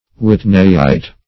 Search Result for " whitneyite" : The Collaborative International Dictionary of English v.0.48: Whitneyite \Whit"ney*ite\, n. [So called after J.D. Whitney, an American geologist.] (Min.) an arsenide of copper from Lake Superior.